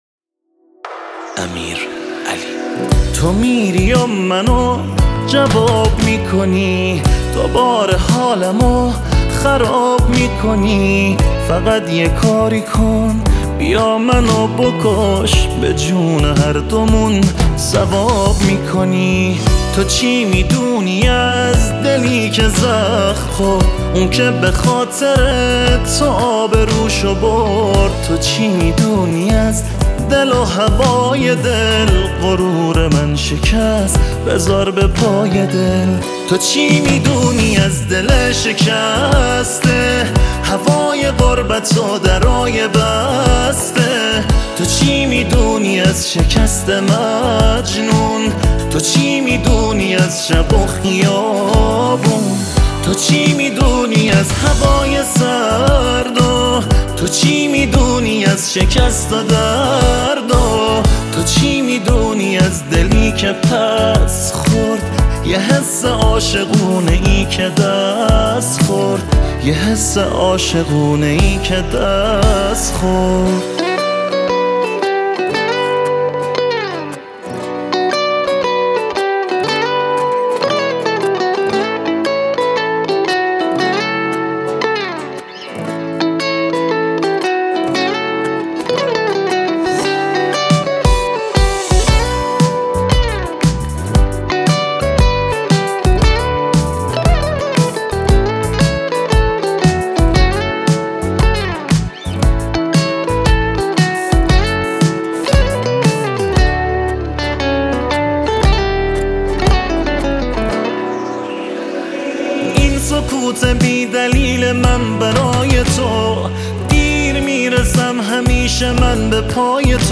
گیتار برقی ……………………………………………………………………………………